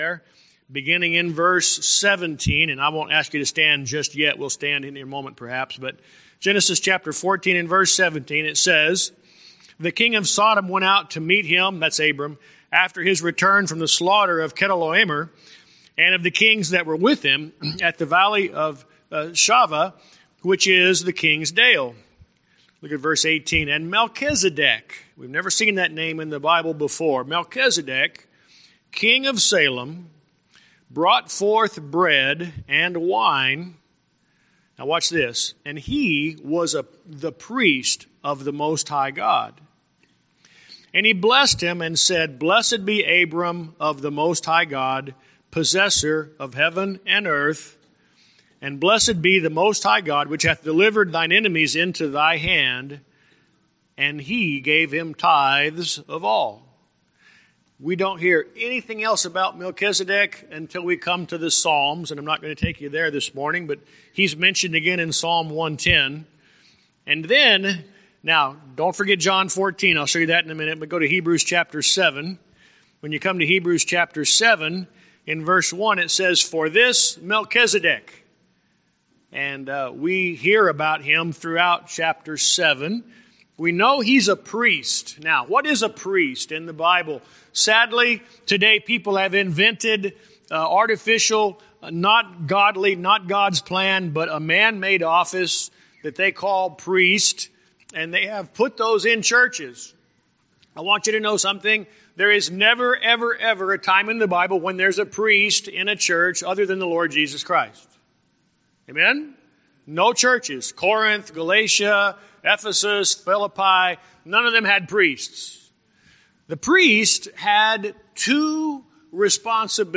Sermons
Email Details Series: Guest Speaker Date